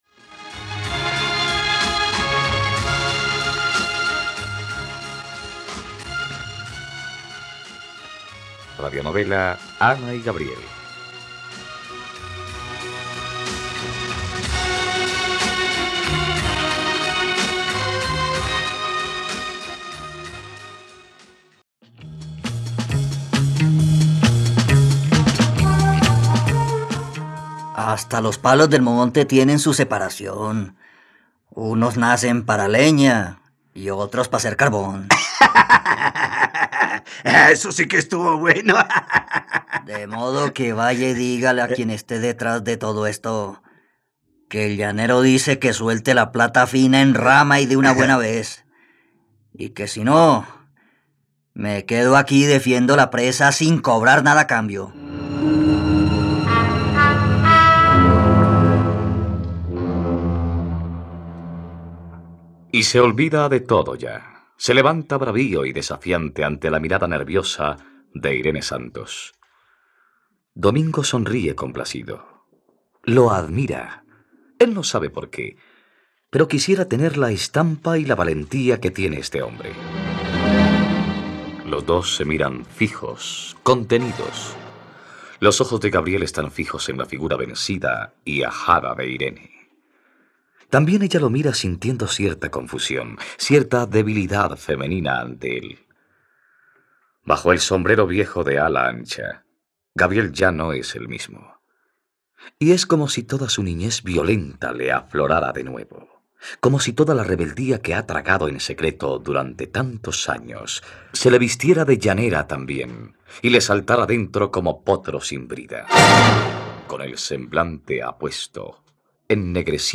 ..Radionovela. Escucha ahora el capítulo 120 de la historia de amor de Ana y Gabriel en la plataforma de streaming de los colombianos: RTVCPlay.